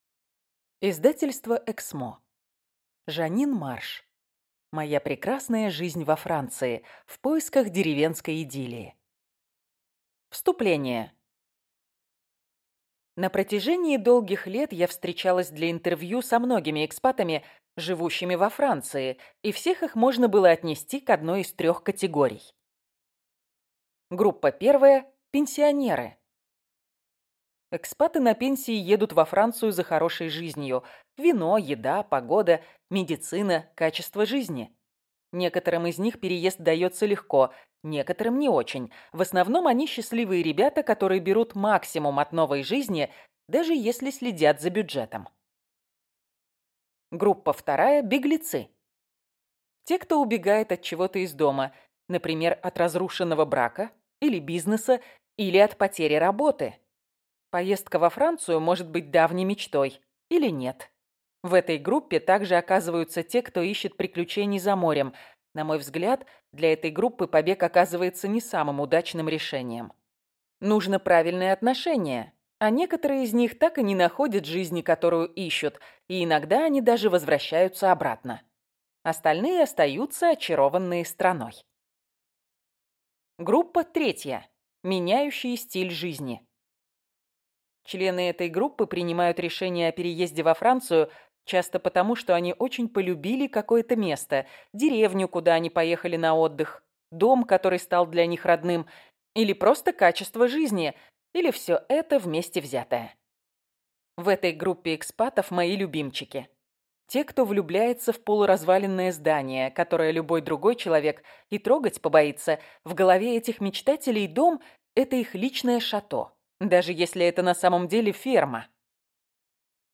Аудиокнига Моя прекрасная жизнь во Франции. В поисках деревенской идиллии | Библиотека аудиокниг